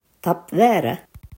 Så här uttalar man ordet tapp värä